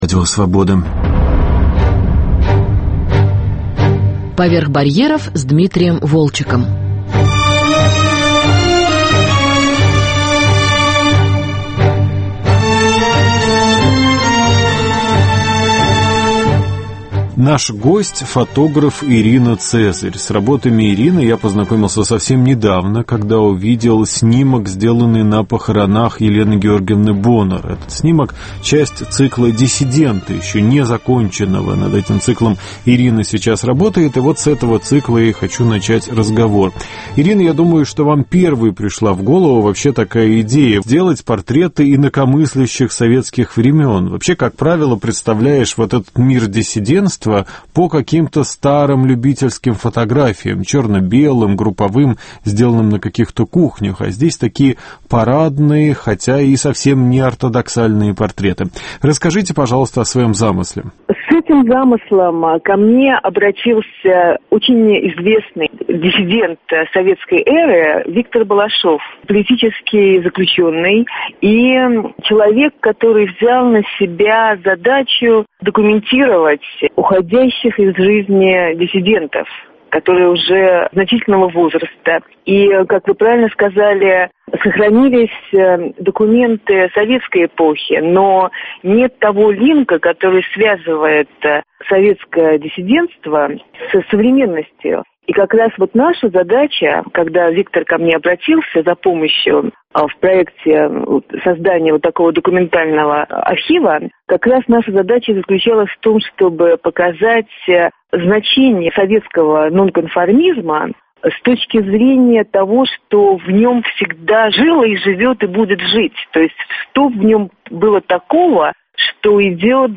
Беседа с актером и поэтом Вадимом Жуком.